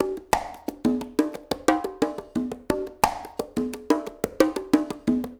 SMP CNGAS2-R.wav